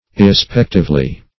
Search Result for " irrespectively" : The Collaborative International Dictionary of English v.0.48: Irrespectively \Ir`re*spec"tive*ly\ ([i^]r`r[-e]*sp[e^]k"t[i^]v*l[y^]), adv.
irrespectively.mp3